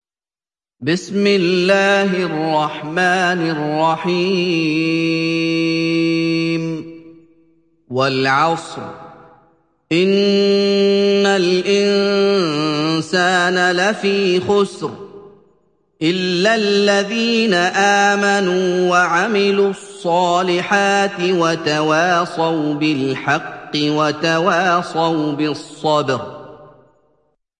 تحميل سورة العصر mp3 بصوت محمد أيوب برواية حفص عن عاصم, تحميل استماع القرآن الكريم على الجوال mp3 كاملا بروابط مباشرة وسريعة